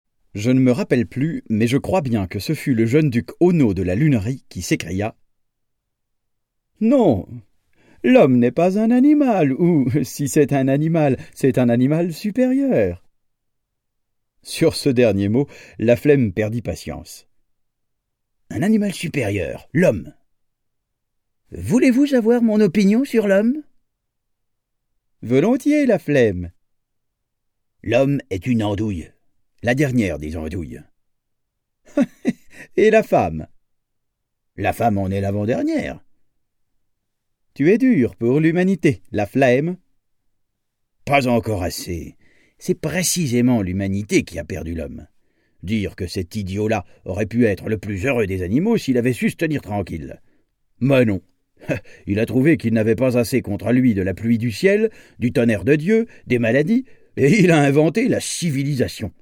Click for an excerpt - Le Chambardoscope de Alphonse Allais